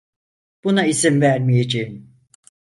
[iˈzin]